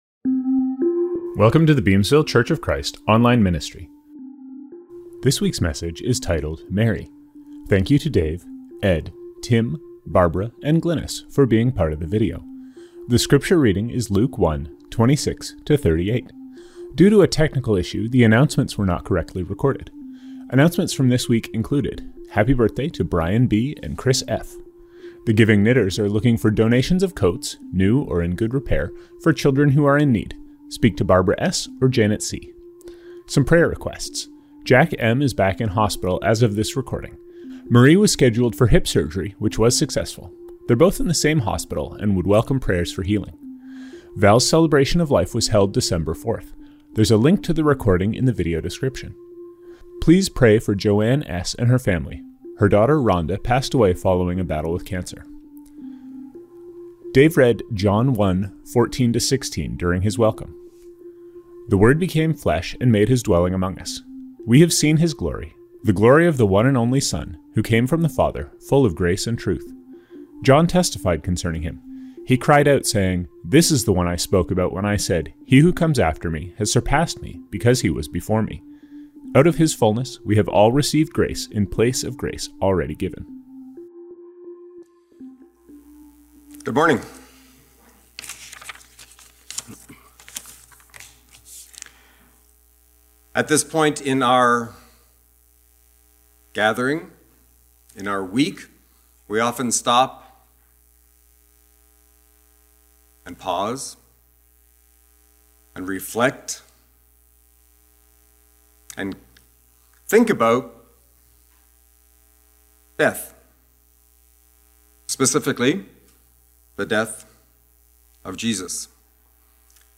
Reading - Luke 1:26-38.